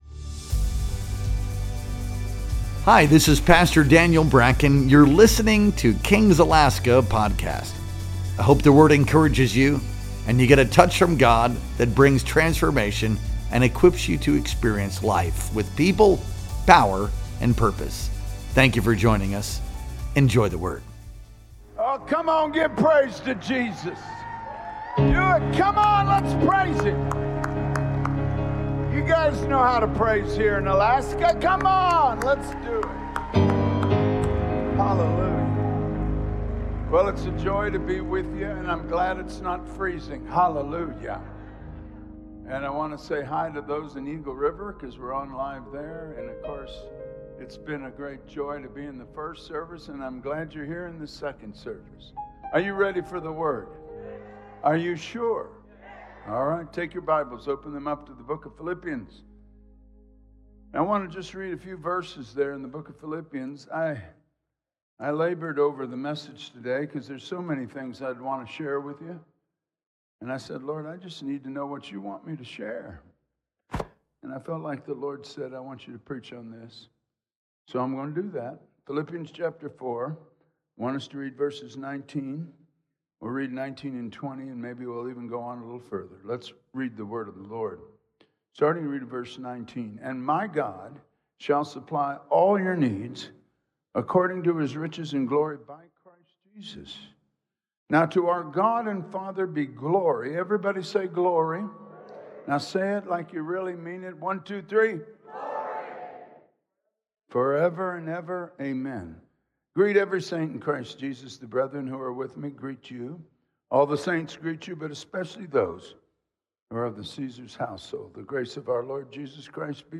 Our Sunday Worship Experience streamed live on May 18th, 2025.